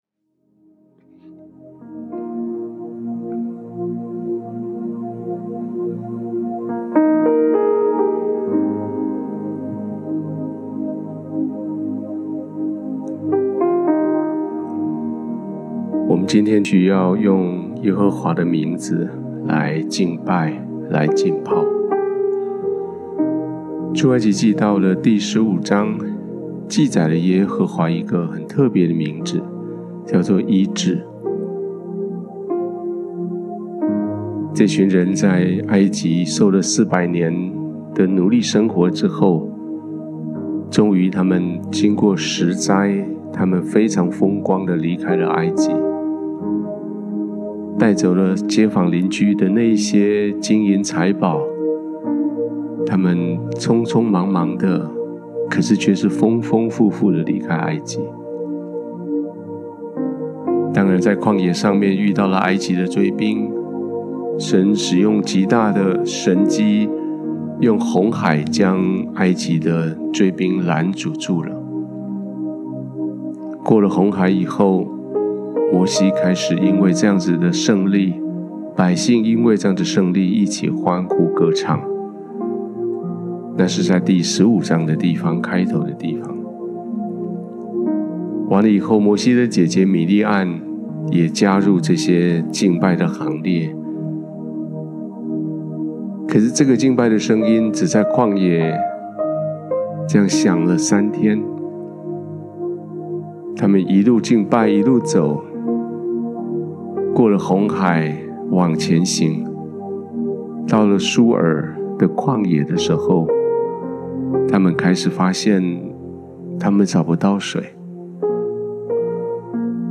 数算俄梅珥第12-13天 浸泡、思想、祷告｜耶和华拉法 （Rapha） 第一音源 第二音源 &n…